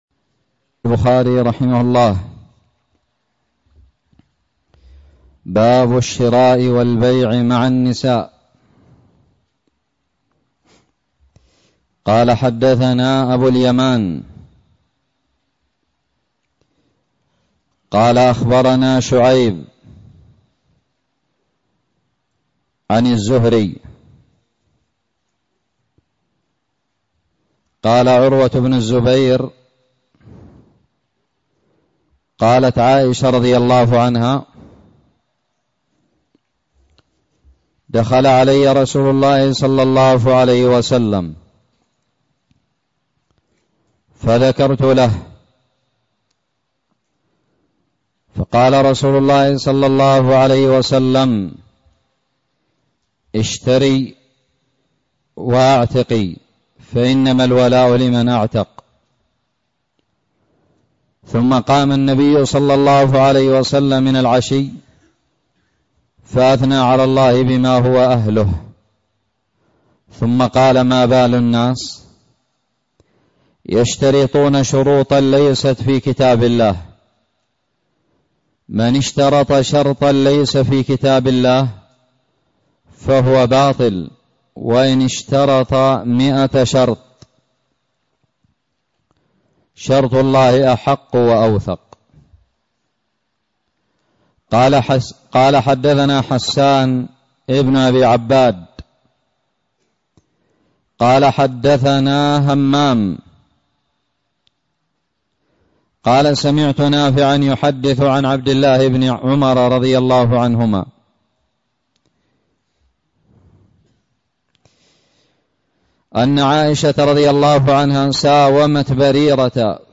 شرح صحيح الإمام البخاري- متجدد
ألقيت بدار الحديث السلفية للعلوم الشرعية بالضالع